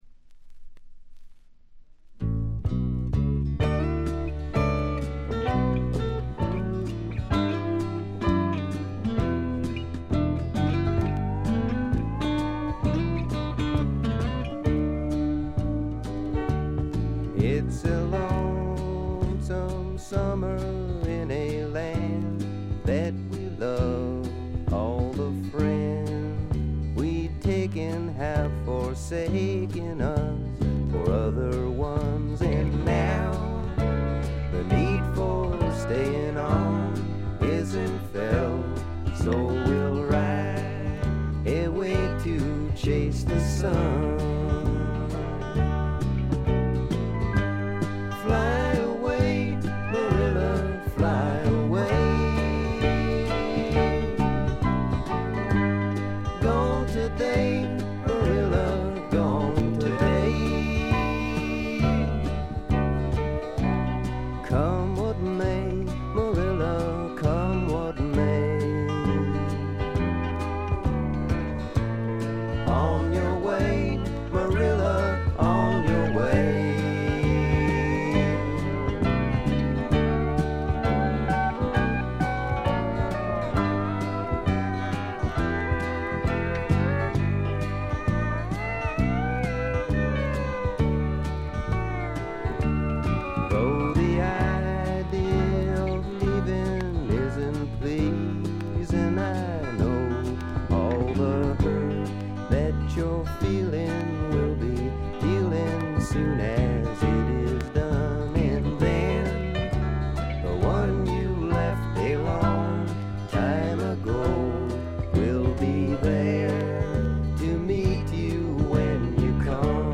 軽微なバックグラウンドノイズにチリプチ少々。
試聴曲は現品からの取り込み音源です。